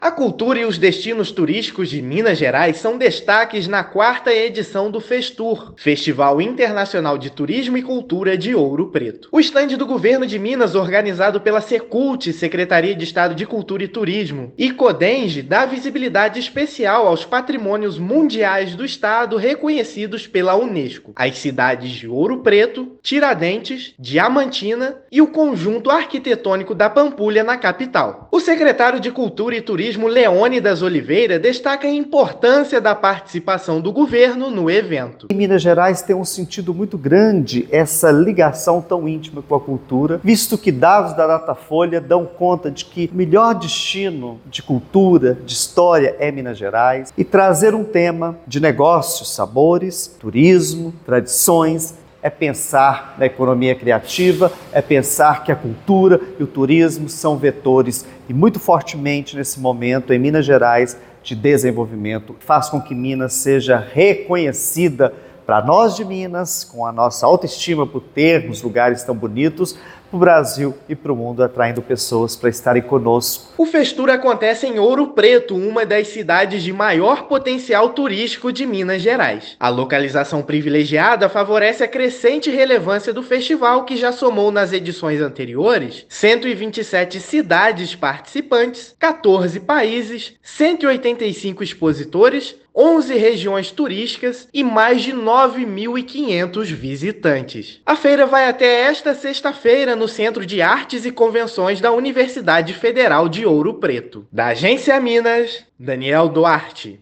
[RÁDIO] Governo do Estado fará promoção do Destino Minas no Festival Internacional de Turismo e Cultura de Ouro Preto
São esperados 4 mil visitantes nos três dias do evento, que terá programação diversa e gratuita desta quarta (5/6) a sexta-feira (7/6). Ouça a matéria de rádio: